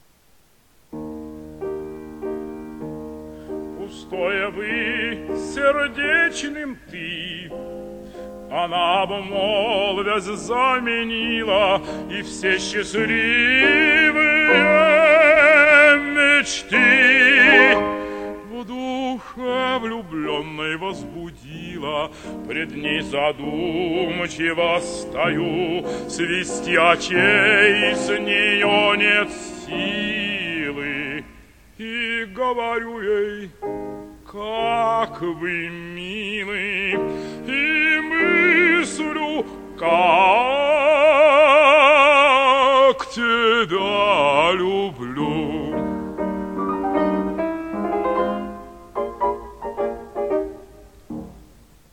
Певцы
Режим: Stereo